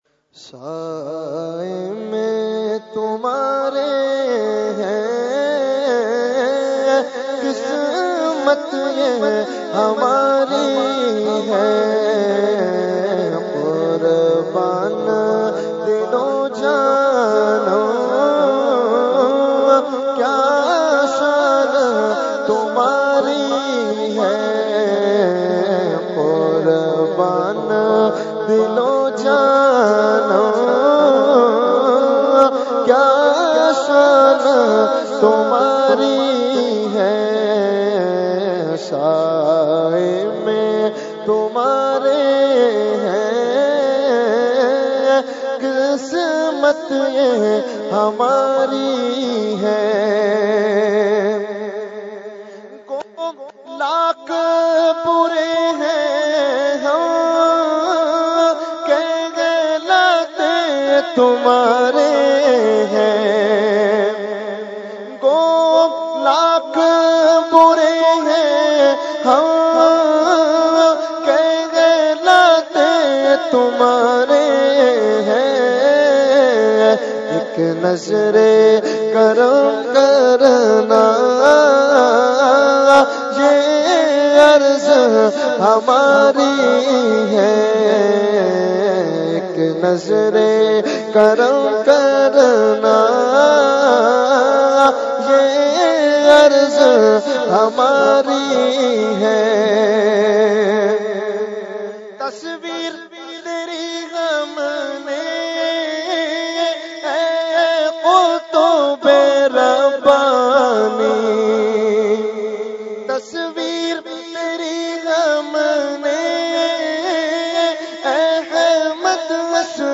Category : Manqabat | Language : UrduEvent : Urs Qutbe Rabbani 2019